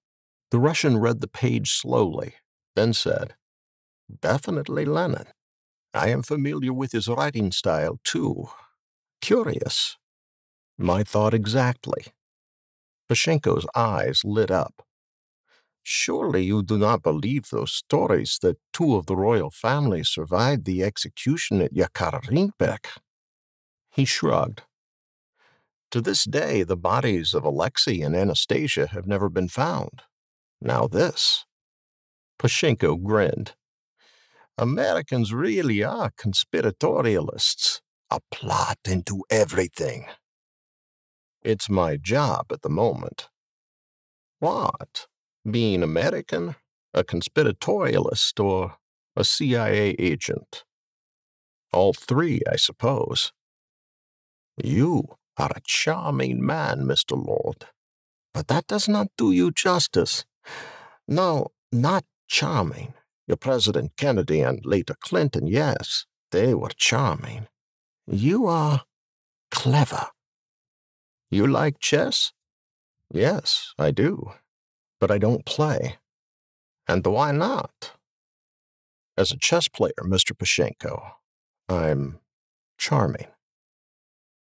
Male
English (North American), English (Neutral - Mid Trans Atlantic)
Adult (30-50)
A storyteller by nature, he can deliver a wide variety of reads that can be playful and fun or serious and trustworthy.
Audiobooks
Fiction - Character Dialogue
Words that describe my voice are guy next door, professional, authoritative.
0525Fiction_-_Character_Dialogue.mp3